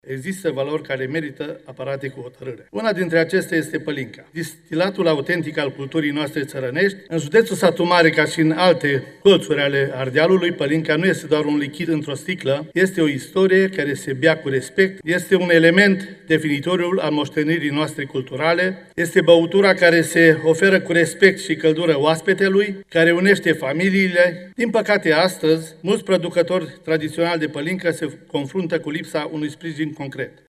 Deputatul PSD Mircea Vasile Govor: „Pălinca nu este doar un lichid într-o sticlă. Este o istorie care se bea cu respect”